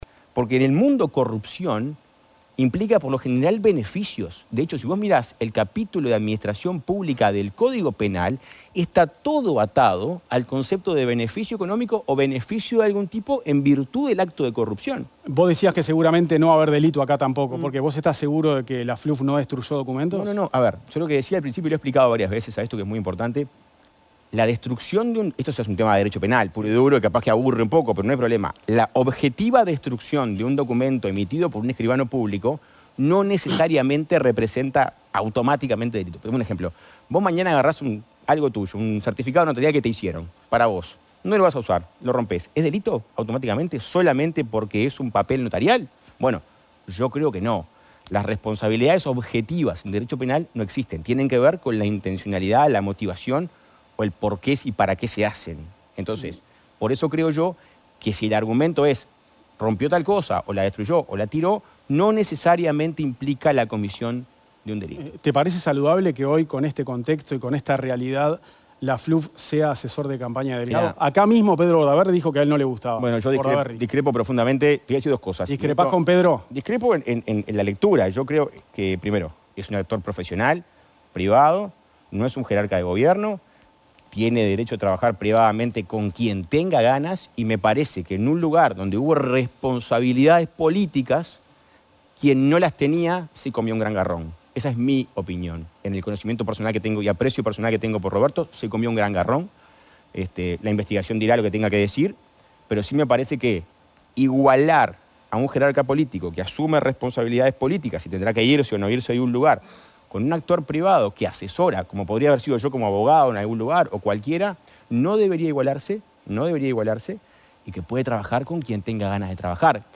El candidato presidencial del Partido Colorado, Andrés Ojeda estuvo en «Séptimo Día» de La Tele y habló sobre su candidatura y el archivo de la causa del caso Marset.